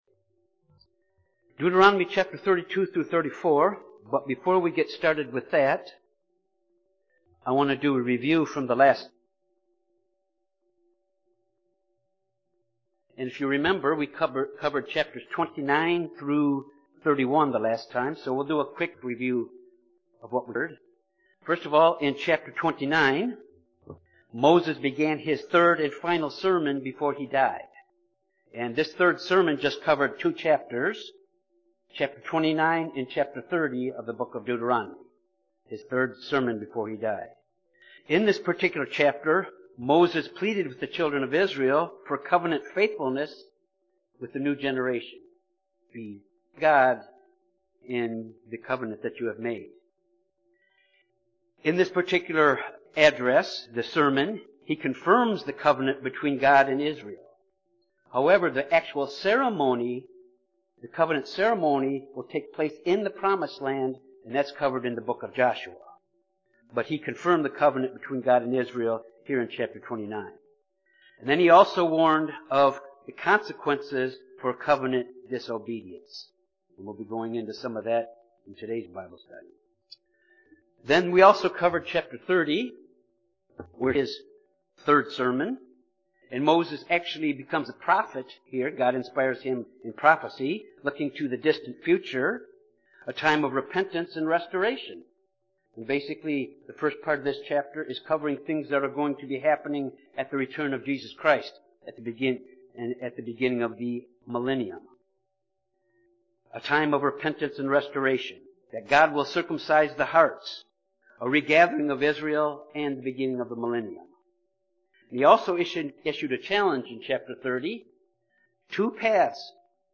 This Bible study completes the book of Deuteronomy. It covers the song of Moses which was written, taught and to be sung by Israel as a reminder of how important it is to obey God and how they and we must never forget that the same creator God who delivered Israel from the slavery of Egypt is our deliverer as well.